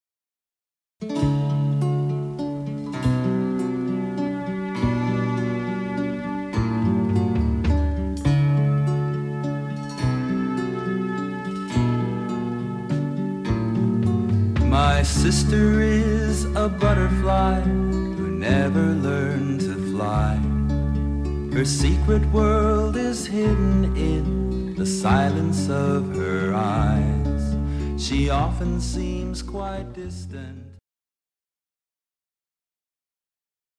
• Audiobook • 00 hrs, 48 mins
Keywords Children disabilities, Down syndrome, Emotions, Feelings, Friendship, New Release, Special needs, Stories with Music